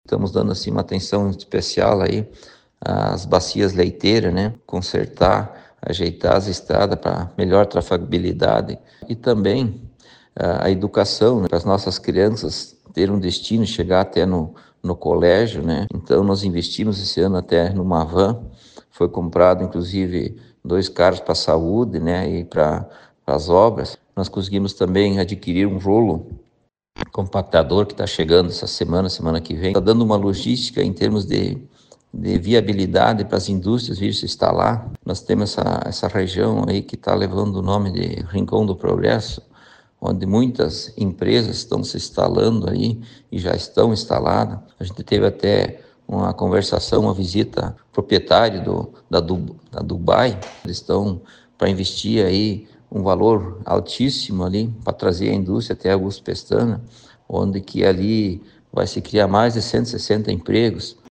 O prefeito de Augusto Pestana, Sérgio Neuberger, ressalta que o Executivo atua em várias áreas para crescimento do município, seja interior ou cidade. Abaixo, áudio do prefeito Neuberger.
a.-SONORA-SERGIO-NEUBERGER-13.05.mp3